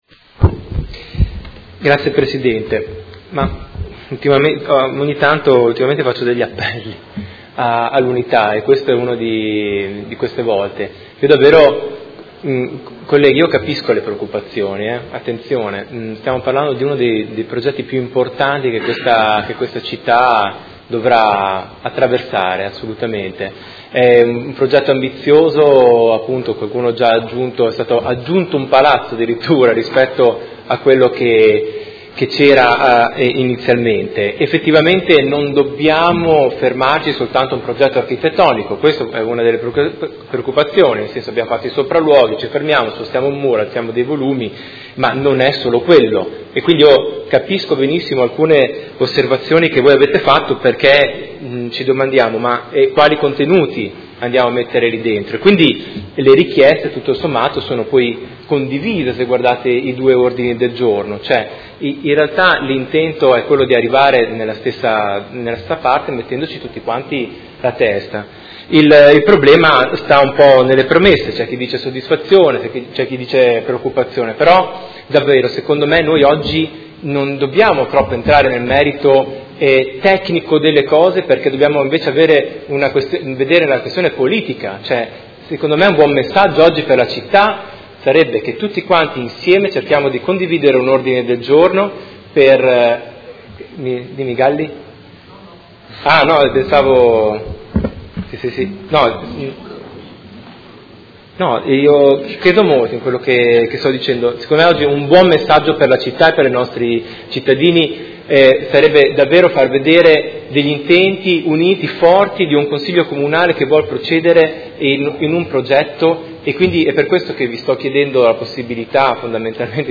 Seduta del 19/04/2018. Dibattito su Ordine del Giorno presentato dal Movimento cinque Stelle avente per oggetto: Prosecuzione del percorso partecipato del Consiglio Comunale sul progetto del Sant’Agostino-Estense e Mozione presentata dai Consiglieri Poggi, Arletti, Lenzini, De Lillo, Forghieri, Venturelli, Bortolamasi, Liotti, Baracchi, Pacchioni e Di Padova (PD) e dal Consigliere Trande (Art.1 MDP/Per me Modena) avente per oggetto: Progetto Polo Culturale Sant’Agostino-Estense: soddisfazione per gli sviluppi del progetto e continuazione del percorso di condivisione